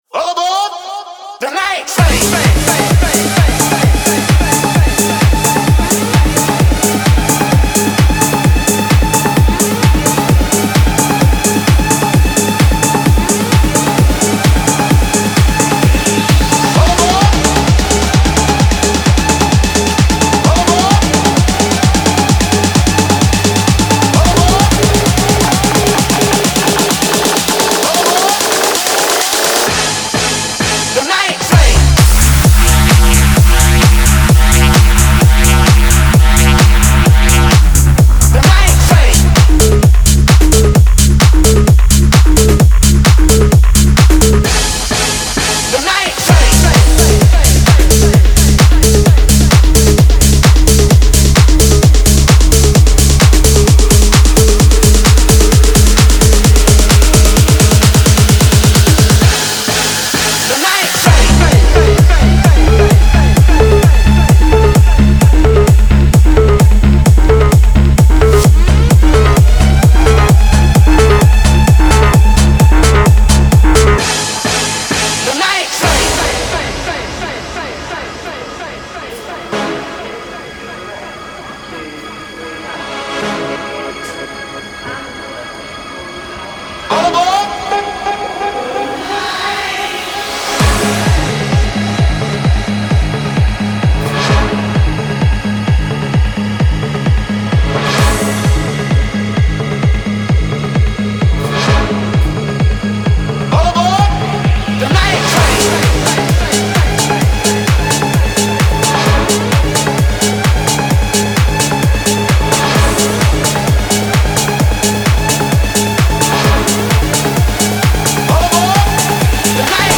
• Жанр: EDM, Dance